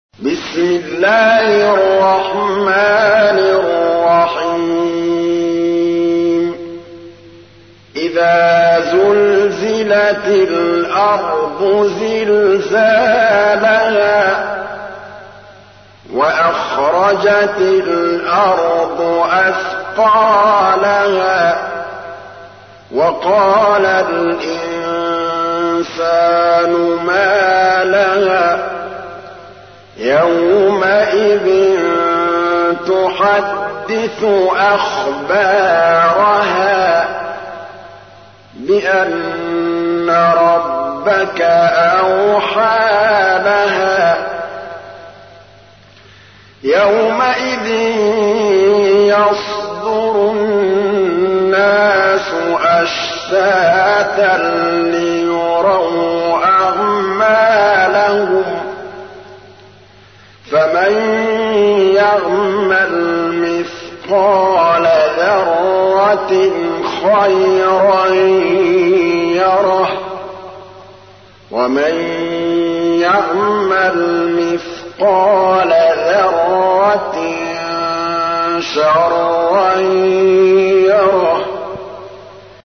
تحميل : 99. سورة الزلزلة / القارئ محمود الطبلاوي / القرآن الكريم / موقع يا حسين